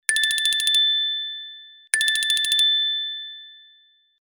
Cute little bell.mp3